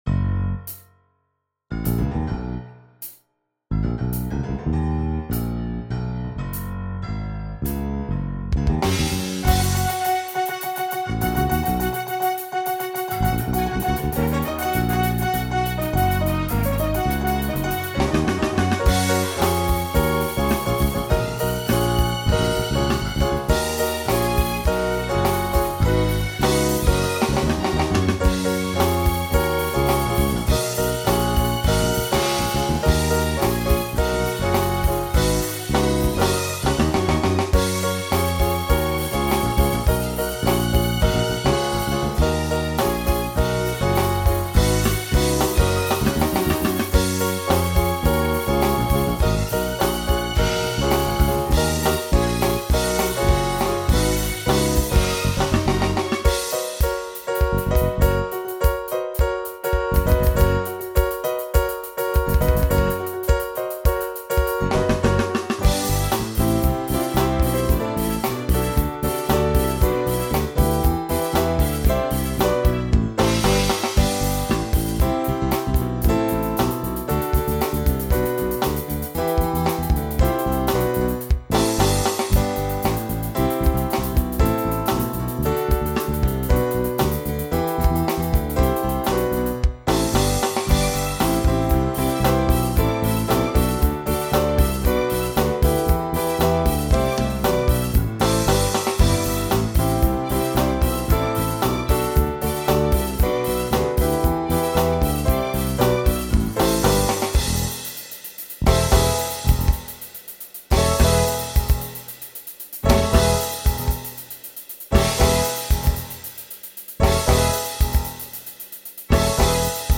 SATB Instrumental combo
Disco , Pop/Dance
Mid-tempo